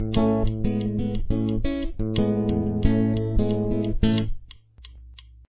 NO PICK on this one guys and gals!
The acoustic is in standard tuning.